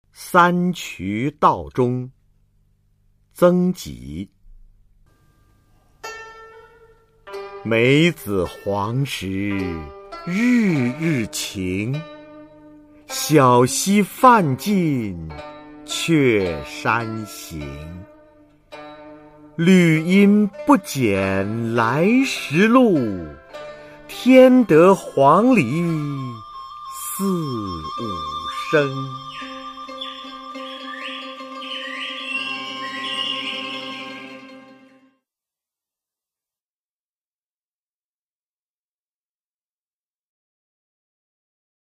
[宋代诗词诵读]曾几-三衢道中（男） 宋词朗诵